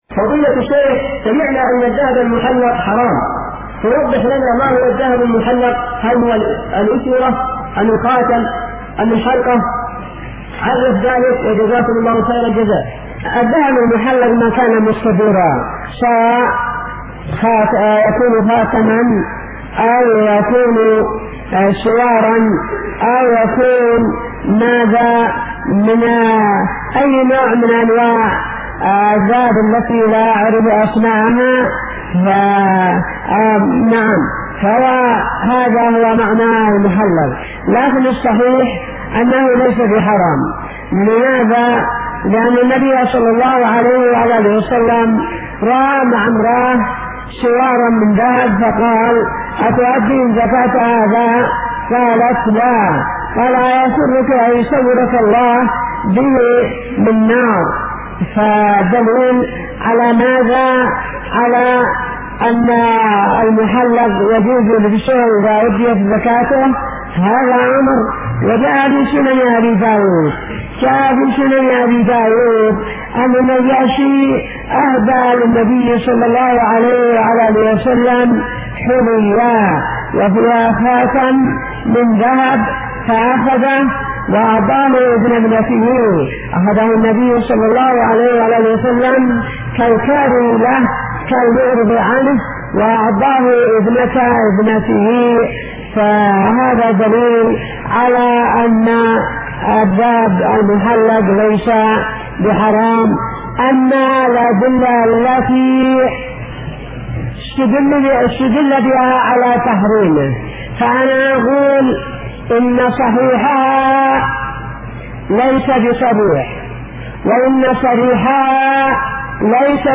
فتاوى